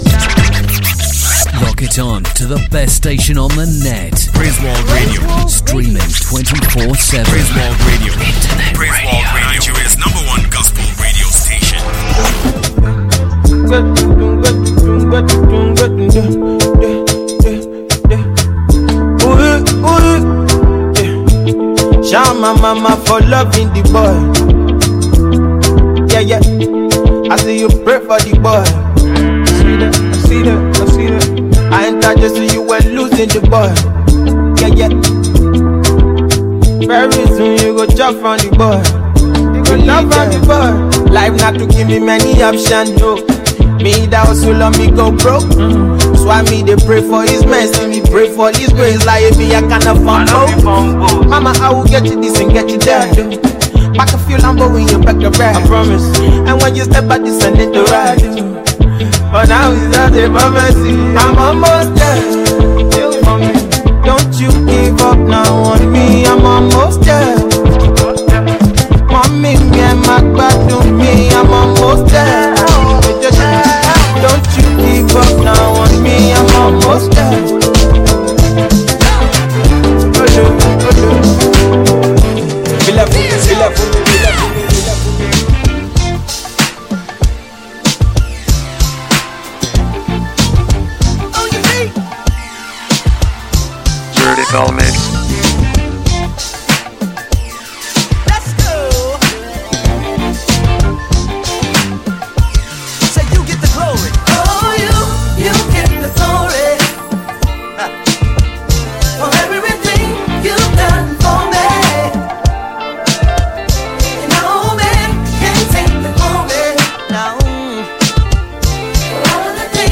in a turnt house party.